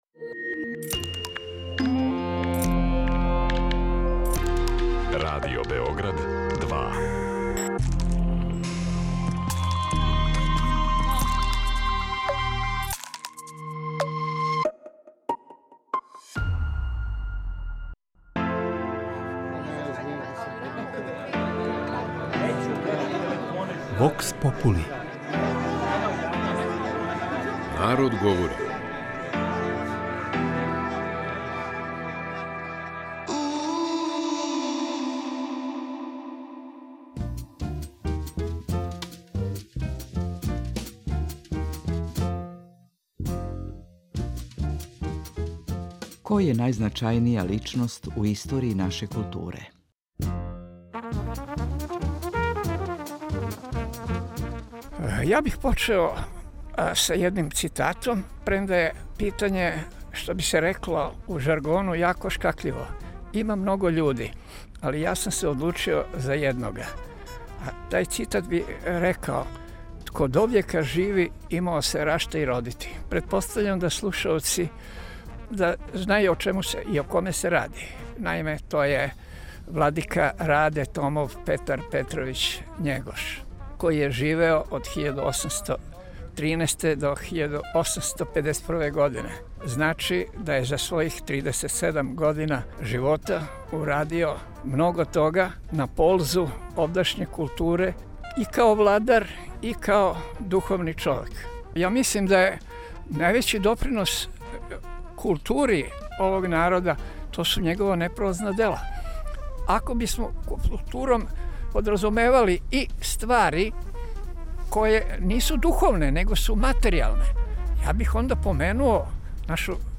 Питали смо наше суграђане коју личност сматрају најзначајнијом за нашу културу.
Вокс попули